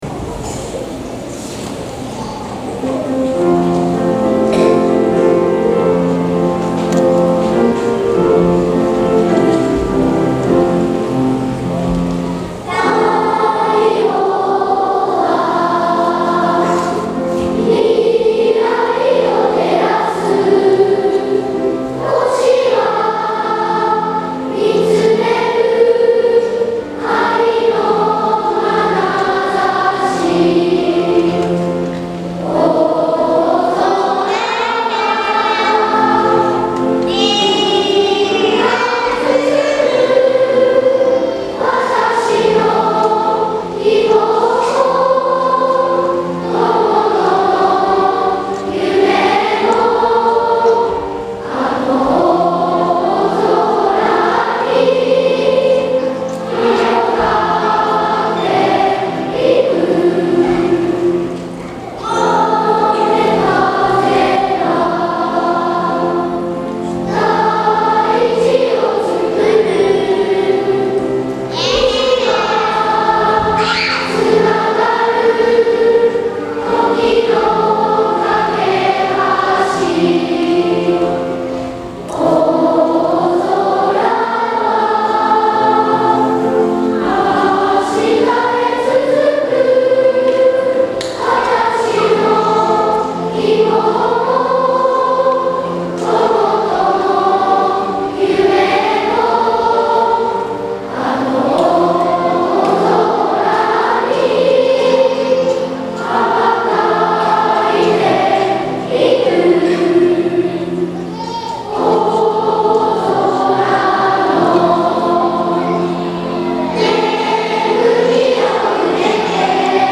大空創立記念コンサート
校歌」会場２部合唱です。